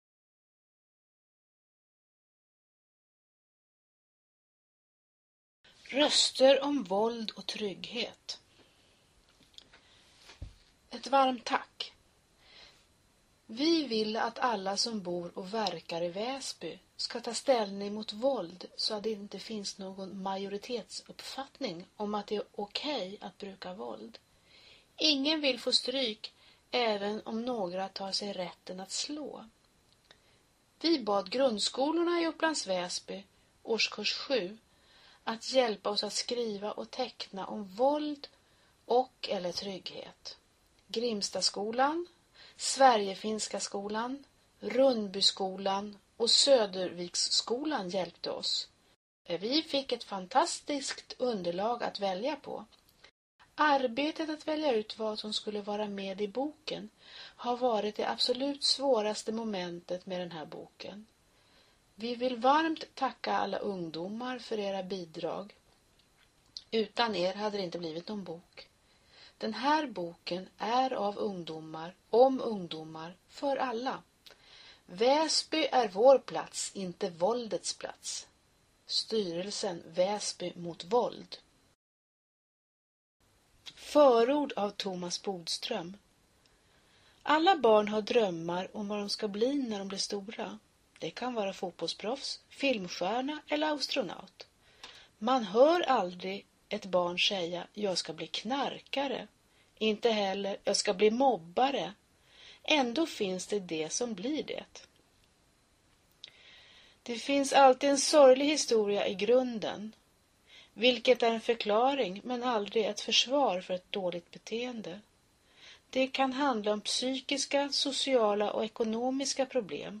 Till boken har vi även gjort en ljudfil som är producerad speciellt med tanke på dyslektiker och mindre barn. Ljudfilen innebär att en berättare läser texten så att lyssnaren endast följa kan med och titta på bilderna i boken.